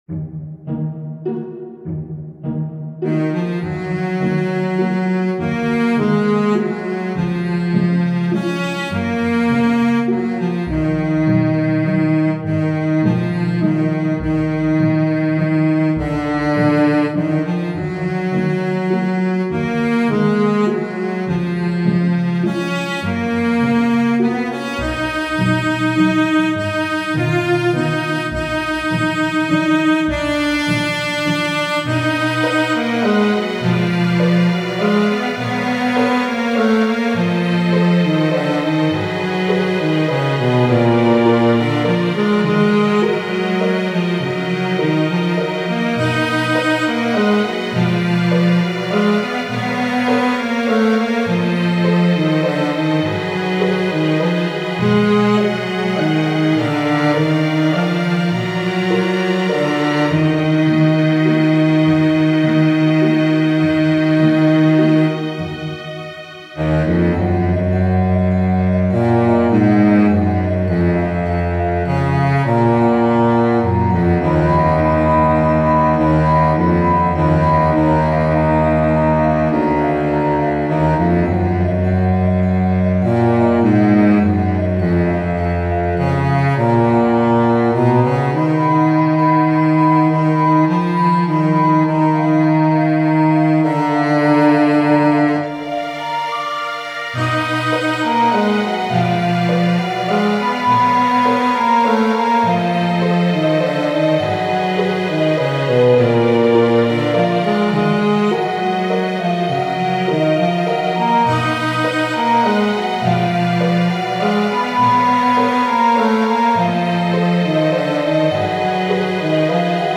ogg(L) 悲愴 重い スロウ
より重厚にずっしりと。